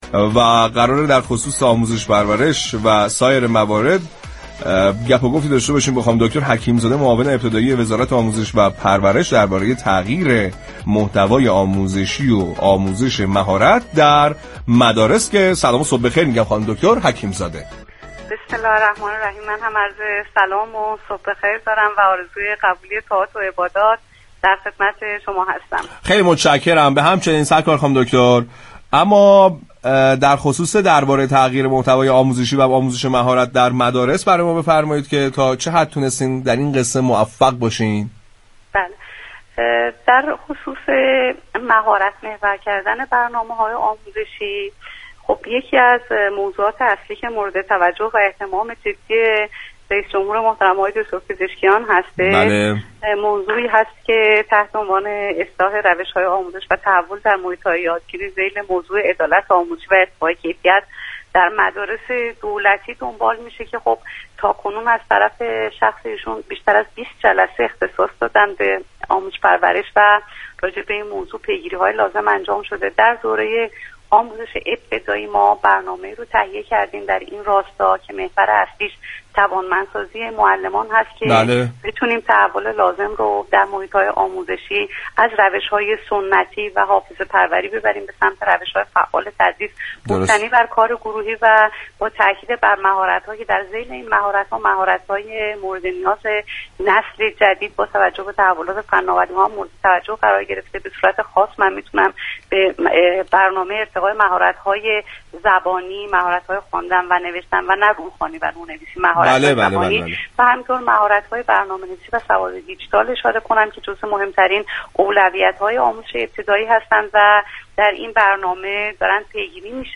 به گزارش پایگاه اطلاع رسانی رادیو تهران، رضوان حكیم زاده معاون آموزش ابتدایی وزارت آموزش و پرورش در گفت و گو با «سعادت آباد» اظهار داشت: یكی از موارد مهم مورد تاكید رئیس جمهور مهارت محور كردن سیستم آموزشی است.